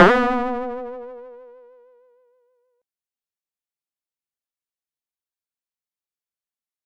spring.wav